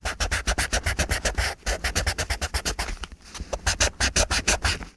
fo_pencil_erasing_02_hpx
Pencil writing and erasing on a tablet of paper. Eraser, Pencil Writing, Pencil